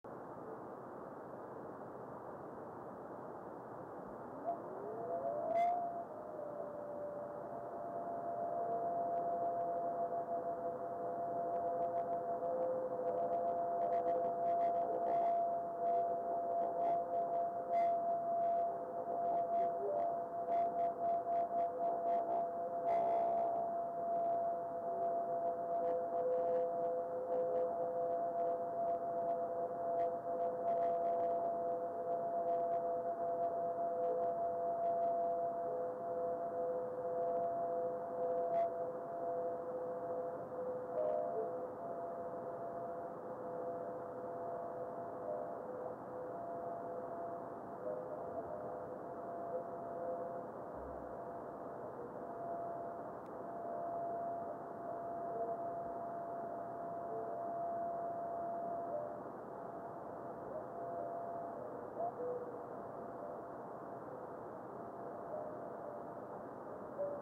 video and stereo sound:
Strong fireball with long ionization.